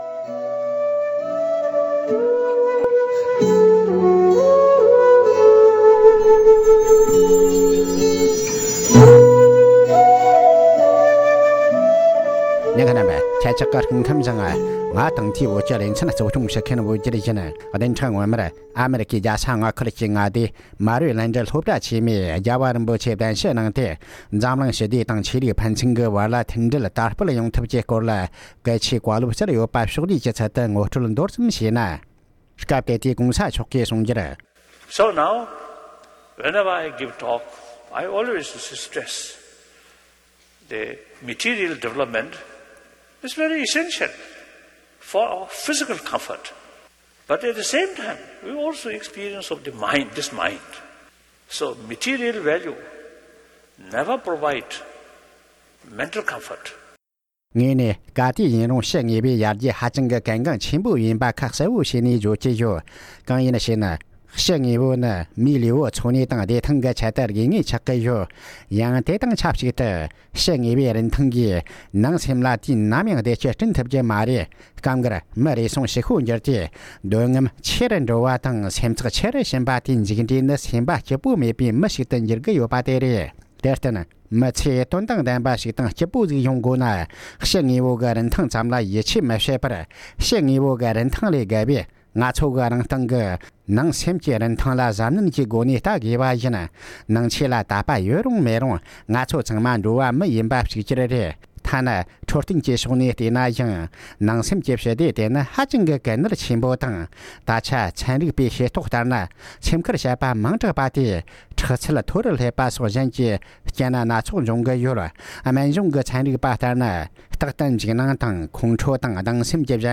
༸གོང་ས་མཆོག་ནས་མེ་རི་ལེནྜ་གཙུག་སློབ་གྲྭ་ཆེན་མོའི་ནང་བཀའ་སློབ།